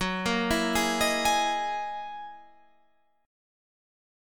Listen to Gb13 strummed